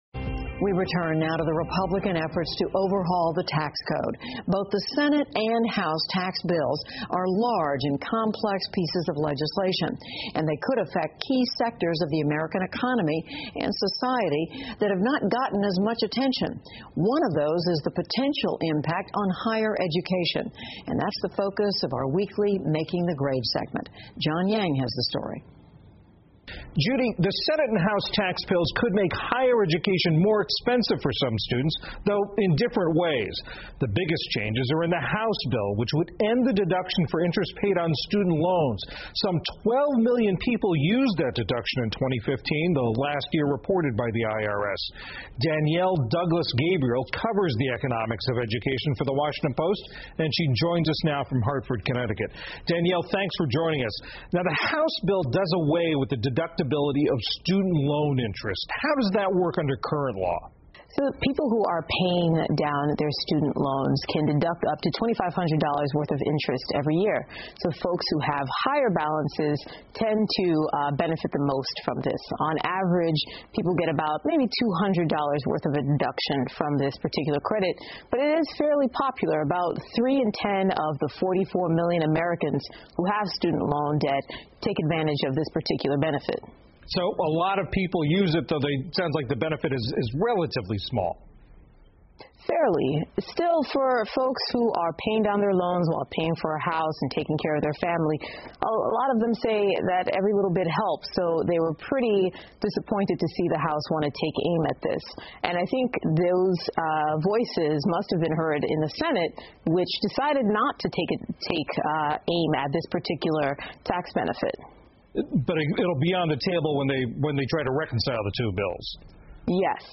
PBS高端访谈:共和党税务改革有可能会影响到学生贷款 听力文件下载—在线英语听力室